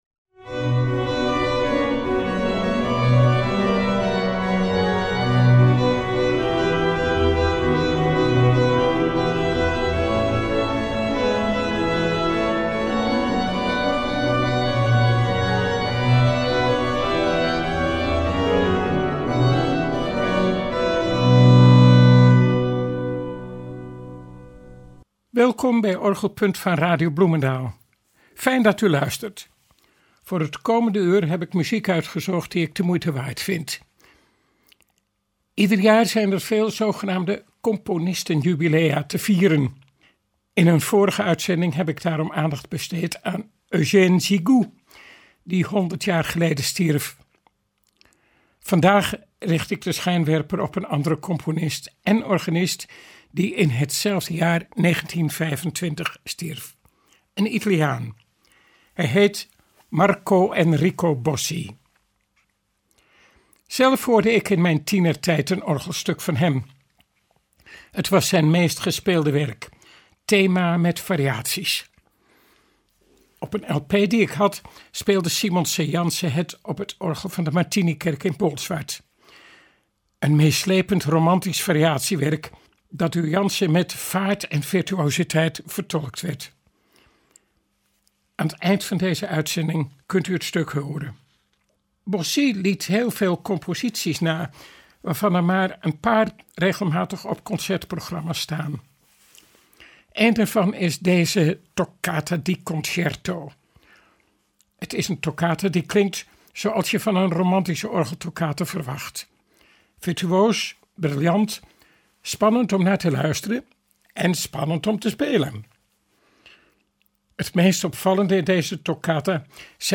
In Orgelpunt laat hij nog veel meer van Bossi’s mooie muziek horen, gespeeld door Italiaanse en Nederlandse organisten.